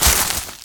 Song: Sfx Activity Soapbox Pumpkin Branch Hit 04